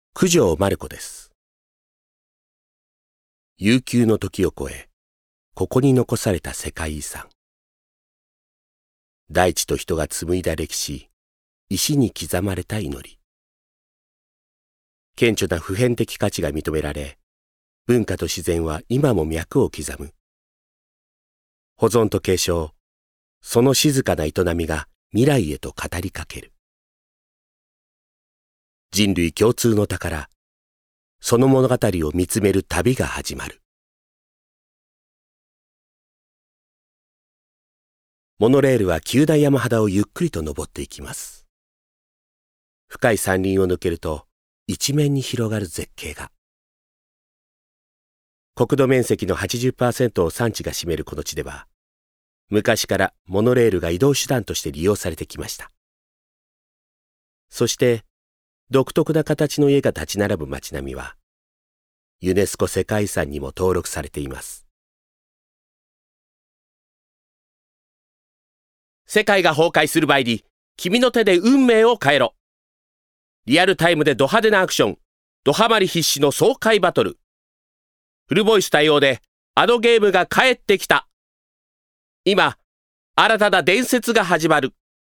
誕生日： 1月27日 血液型： O型 身 長： 168cm 出身地： 東京都 趣味・特技： ドライブ・音楽鑑賞・物事をまとめ進める事 資格： 第1種普通自動車運転免許・1級建築施工管理技士・1級建築施工監理技術者免許 音域： Ｆ2＃～Ｅ4♭
VOICE SAMPLE